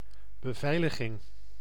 Ääntäminen
IPA: [syʁ.te]